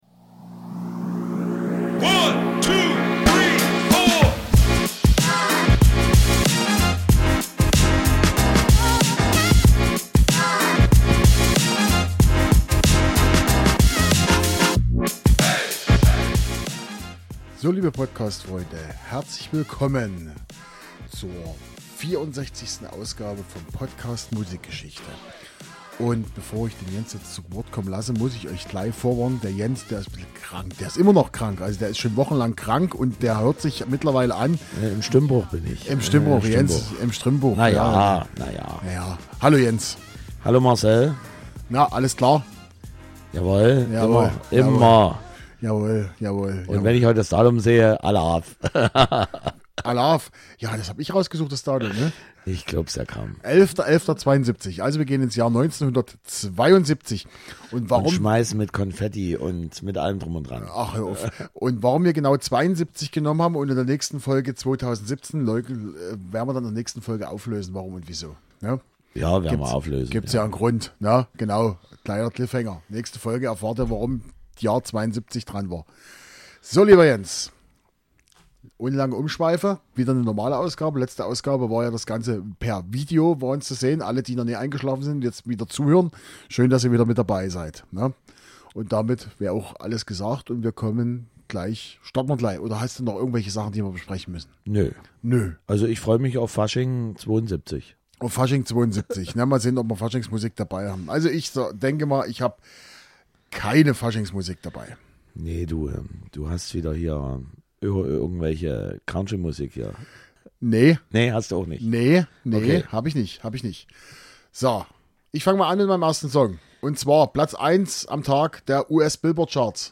Diesmal gibt es wieder Reggae-Music und drei typische Soft-Pop-Songs der 70er Jahre. Dazu sprechen wir über Telefonzellen, Kylie Minogue und Rammstein.
Bei der Aufnahme dieser Folge hatten wir so ein, zwei kleine Tonprobleme, die wir in der Nachbearbeitung nicht beseitigen konnten.